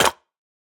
Minecraft Version Minecraft Version 25w18a Latest Release | Latest Snapshot 25w18a / assets / minecraft / sounds / block / mud_bricks / step2.ogg Compare With Compare With Latest Release | Latest Snapshot
step2.ogg